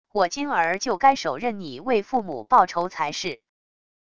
我今儿就该手刃你为父母报仇才是wav音频生成系统WAV Audio Player